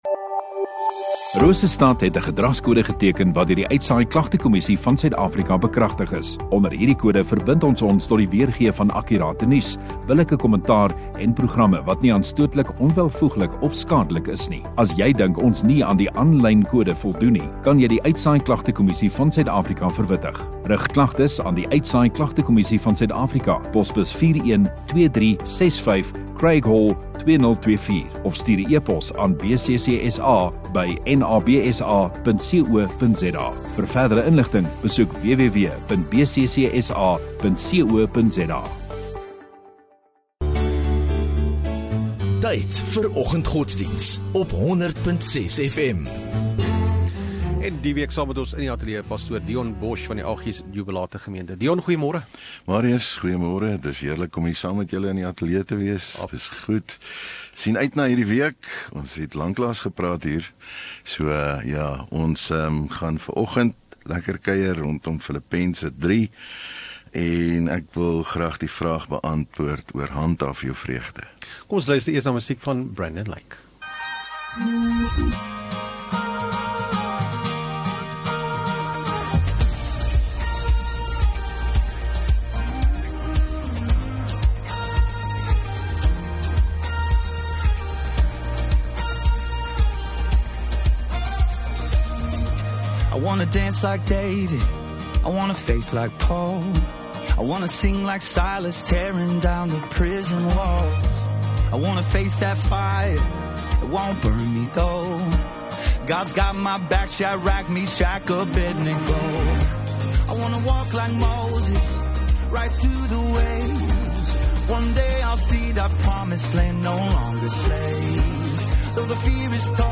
21 Oct Maandag Oggenddiens